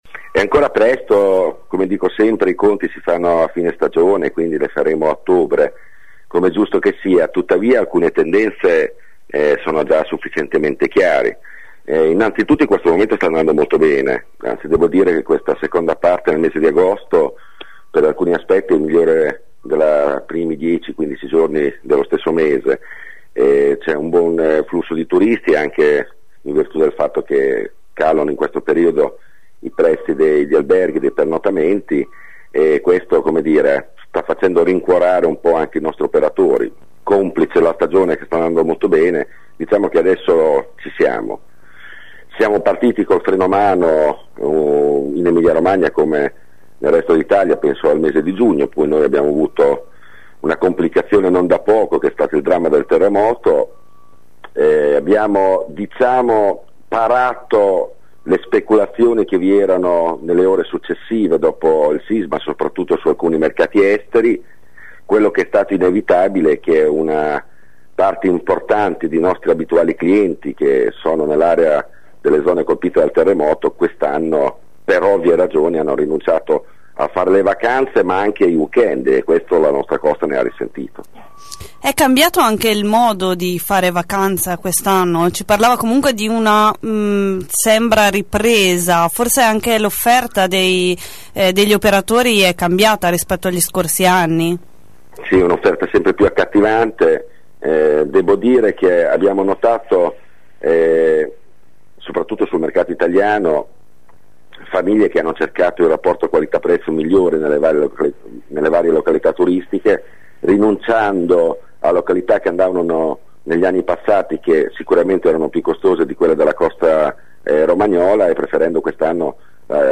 Lo ha detto l’assessore al turismo di Viale Aldo Moro, Maurizio Melucci, ospite questa mattina ad AngoloB. Pur non potendosi fare un bilancio della stagione, qualche tendenza la si può già individuare secondo l’assessore: crescono i turisti stranieri (russi in testa, e poi i soliti tedeschi e svizzeri) e la vacanza degli italiani si fa sempre più mordi-e-fuggi e contenuta nelle spese (complice la crisi).